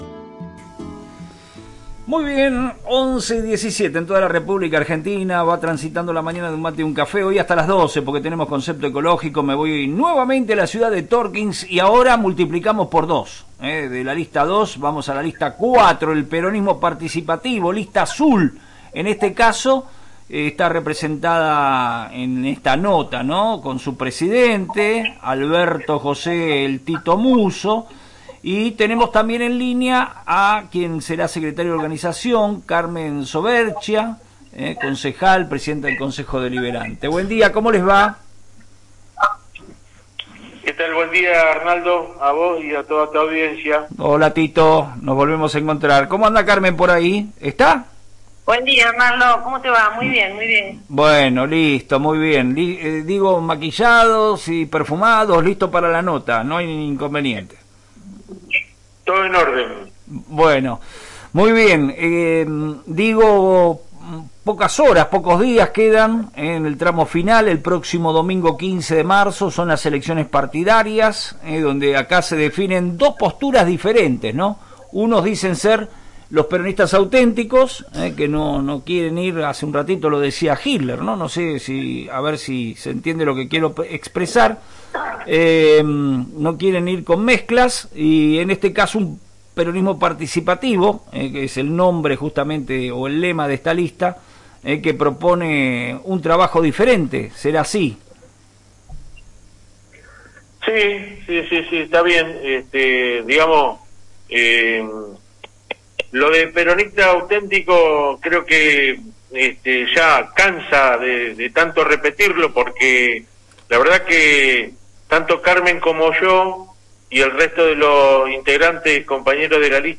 En una entrevista cargada de definiciones políticas en FM Reflejos, los candidatos de la Lista 4 respondieron a los cuestionamientos de la oposición interna de cara al próximo domingo 15 de marzo.